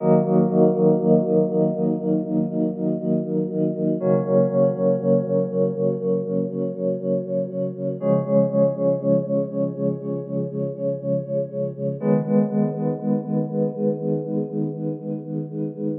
标签： 120 bpm Ambient Loops Pad Loops 2.69 MB wav Key : C
声道立体声